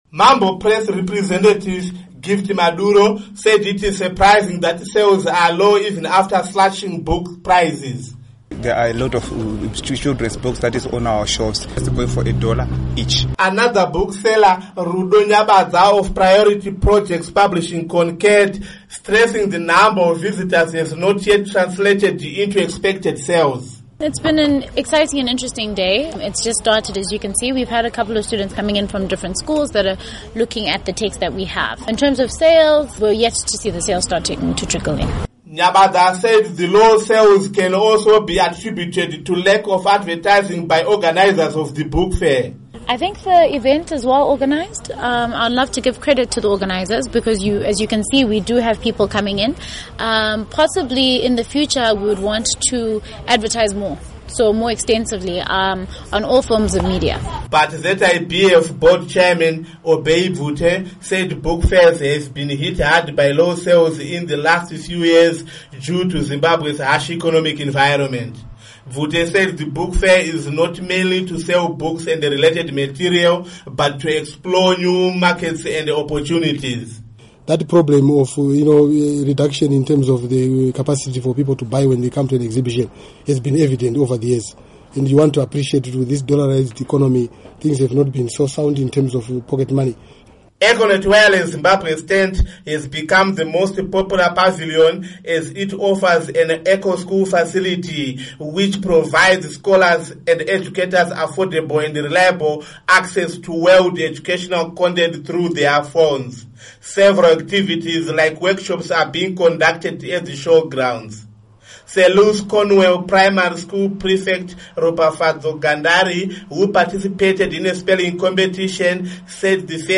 Book Fair Report